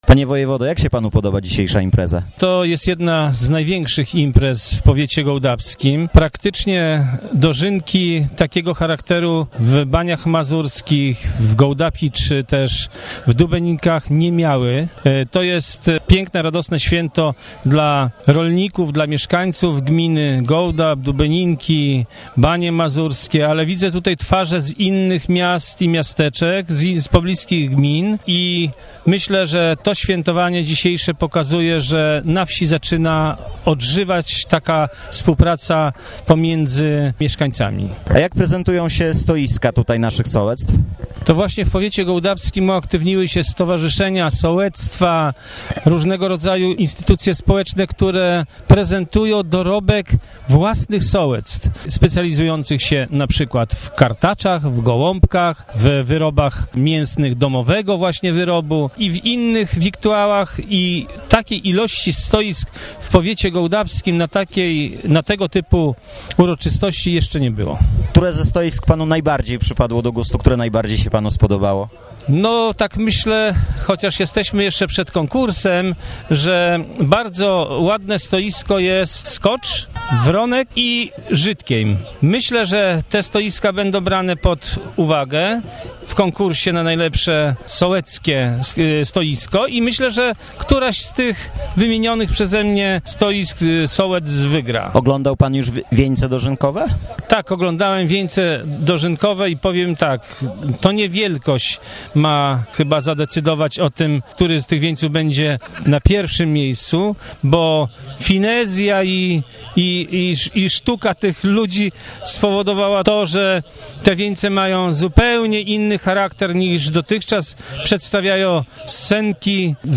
mówi Marian Podziewski, wojewoda warmińsko-mazurski